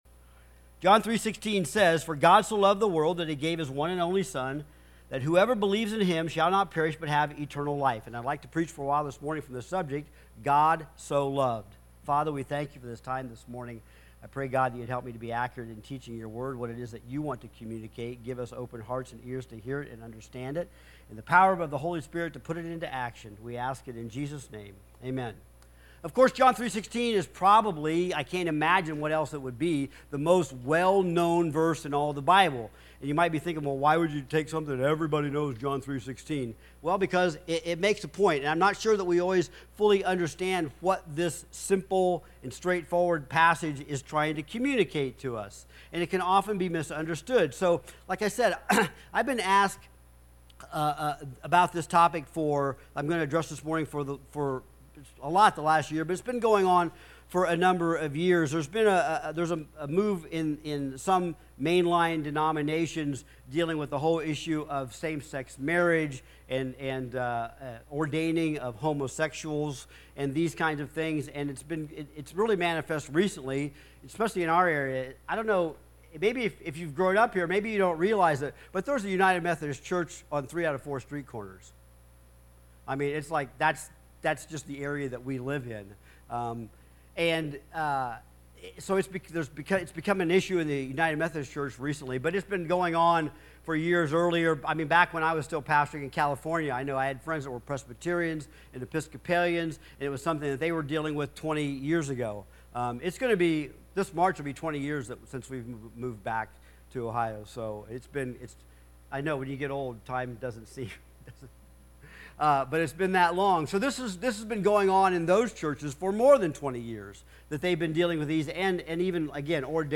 Sermons | Ohio City Community Church of God